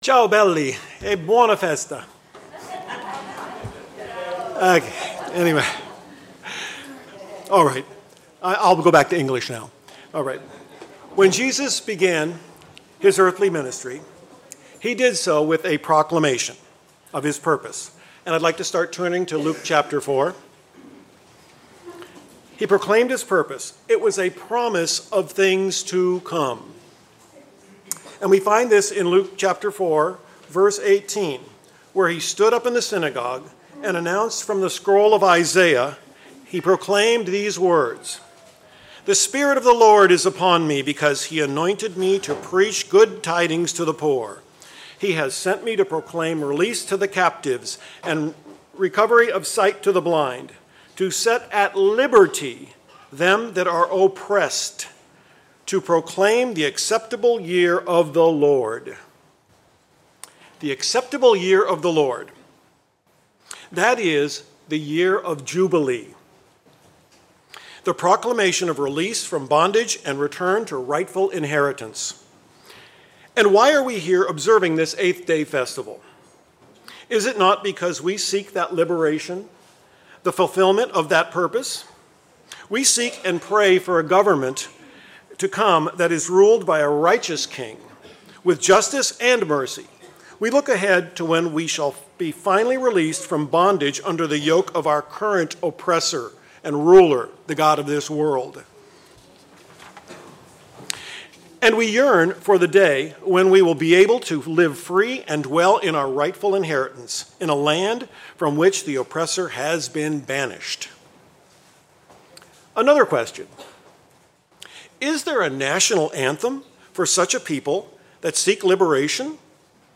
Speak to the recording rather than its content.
FoT 2025 Sabaudia (Italy): Last Great Day (afternoon)